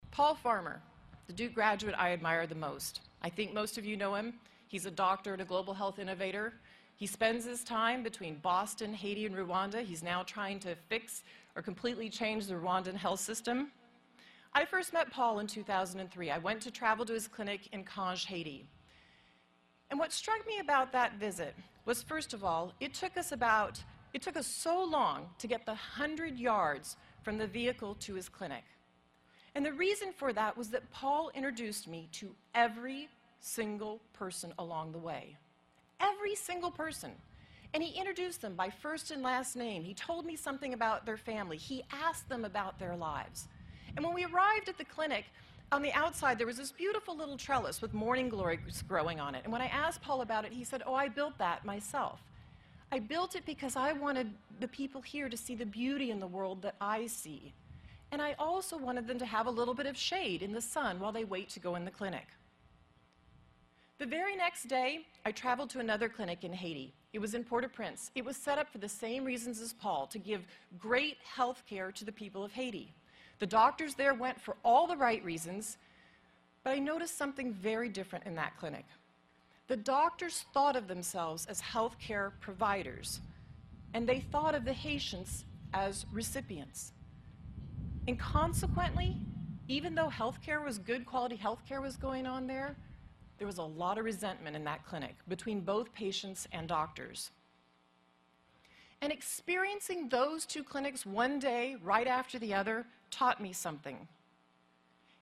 公众人物毕业演讲第347期:梅琳达2013在杜克大学(6) 听力文件下载—在线英语听力室